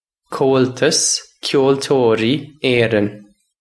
Comhaltas Ceoltóirí Éireann Kole-tus Kyole-toe-ree Ayrun
This is an approximate phonetic pronunciation of the phrase.